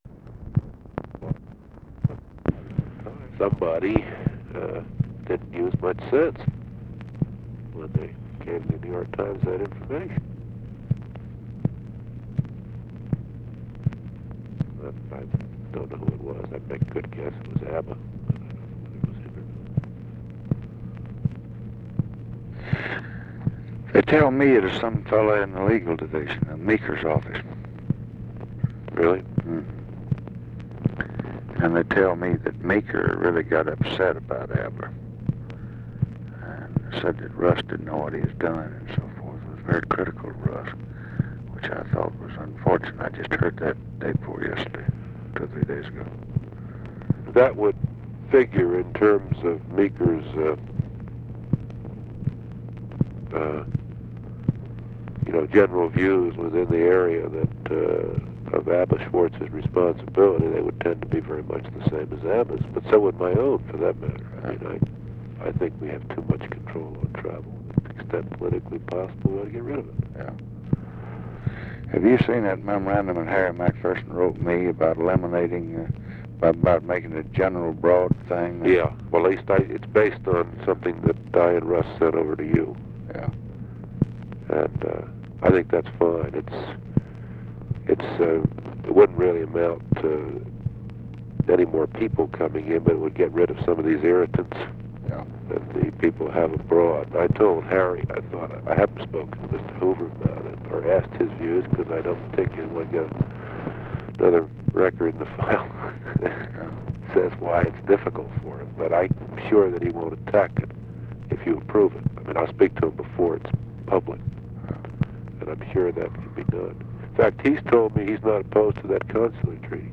Conversation with NICHOLAS KATZENBACH, March 26, 1966
Secret White House Tapes